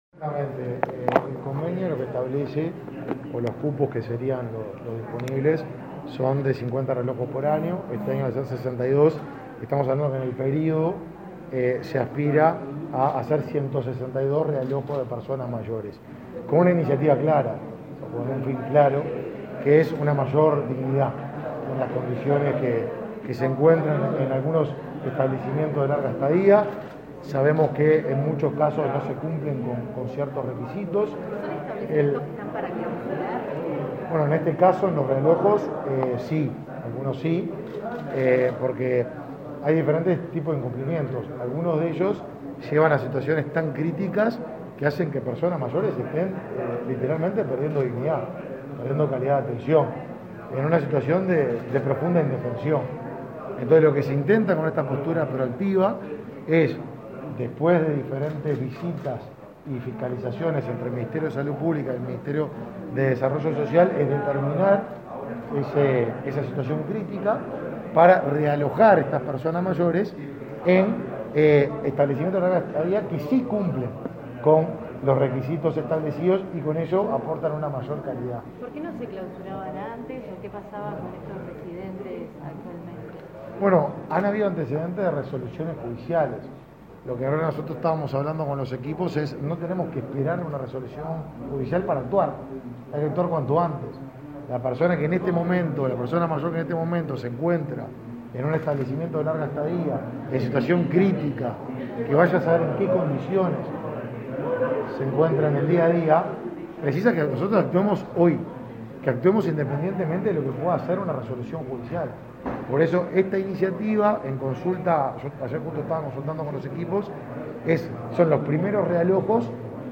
Declaraciones a la prensa del ministro Martín Lema
Declaraciones a la prensa del ministro Martín Lema 08/02/2022 Compartir Facebook X Copiar enlace WhatsApp LinkedIn El titular del Ministerio de Desarrollo Social, Martín Lema, participó este martes 8 en el lanzamiento del programa para el realojo de personas mayores y, luego, dialogó con la prensa.